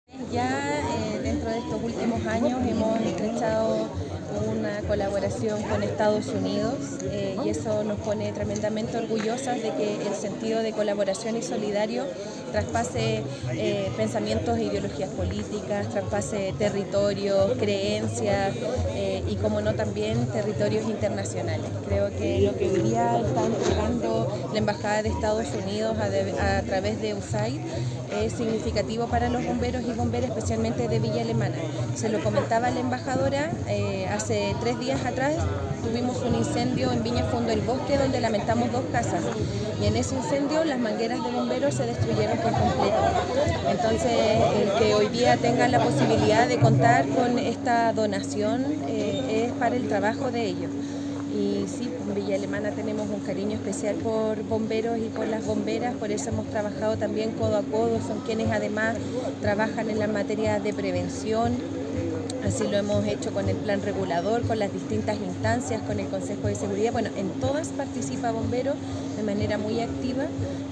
Con una ceremonia celebrada en el cuartel de calle Arrieta, entre la embajada de Estados Unidos, el Cuerpo de Bomberos y la Municipalidad de Villa Alemana, se dio a conocer la entrega de 50 mangueras donadas por la Agencia estadounidense para el Desarrollo Internacional (USAID), a las cuatro compañías de la comuna.
La alcaldesa de Villa Alemana, Javiera Toledo, expresó al respecto que